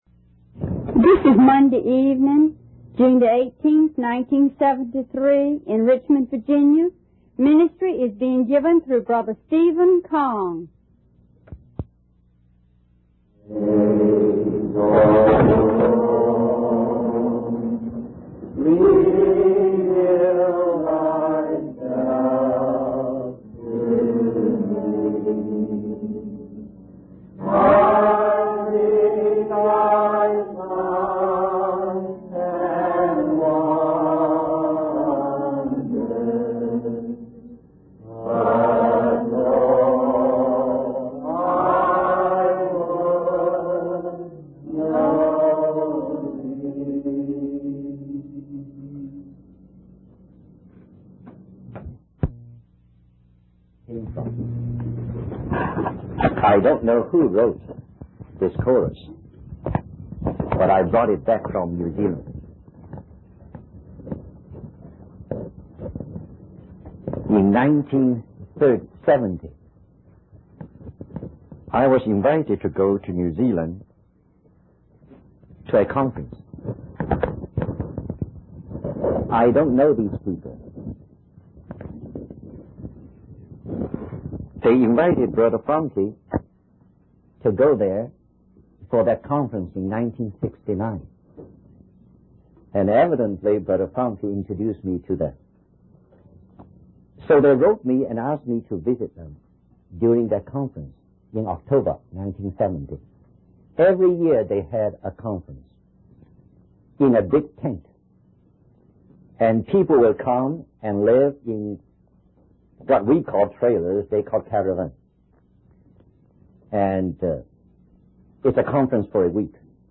He explains that the phrase 'the Lord is near' refers to the present, rather than the second coming of Jesus. The speaker shares a theme song from a conference he attended, which expresses the desire to know the Lord personally, beyond just witnessing signs and wonders. He concludes by stating that the joy of the Lord is our strength and that by focusing on Him, we can overcome any challenges in life.